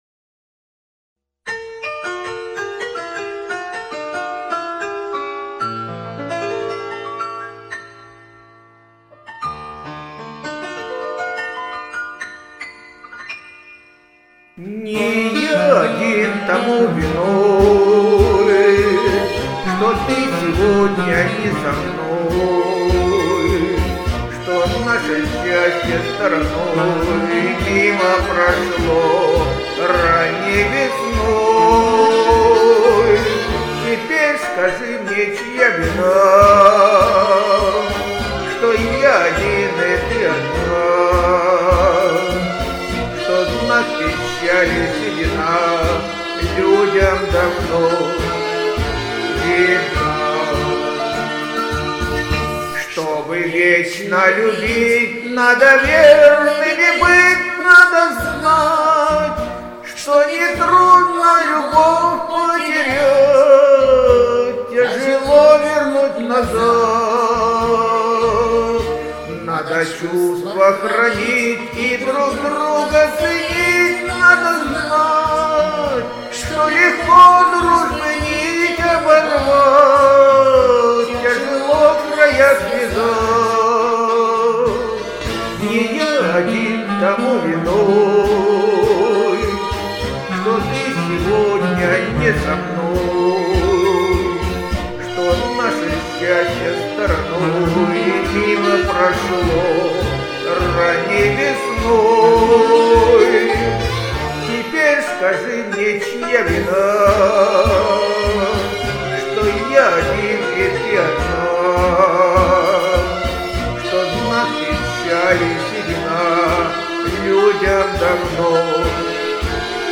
Молдавский шлягер 50-х г.г. 20-го в.